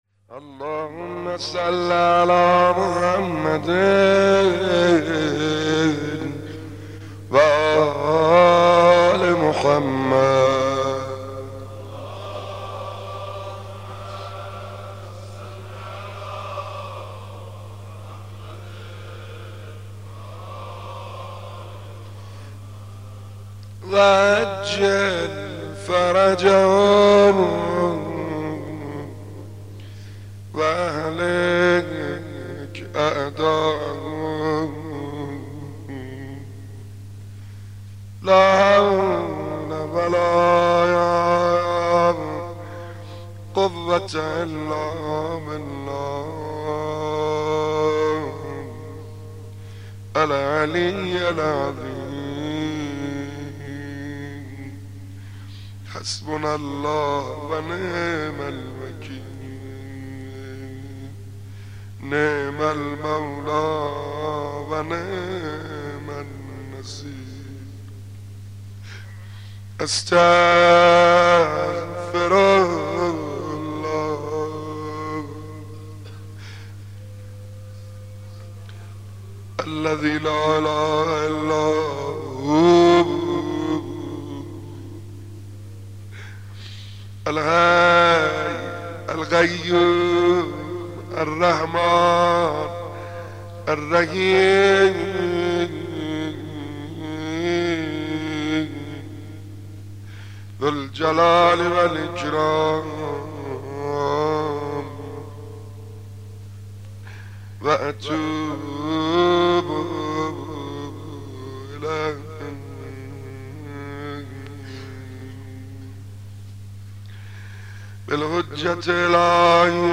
مداح
مناسبت : شب پنجم محرم
مداح : سعید حدادیان